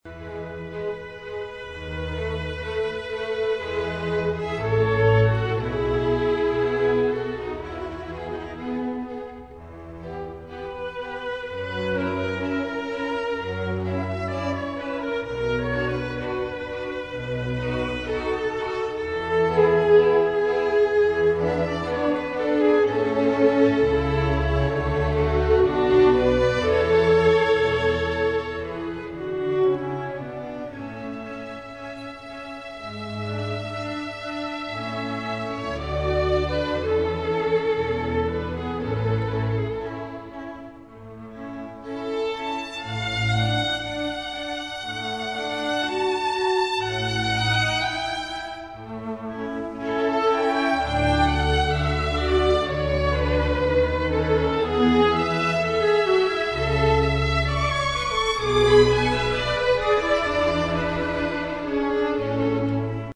conciertoviolines.mp3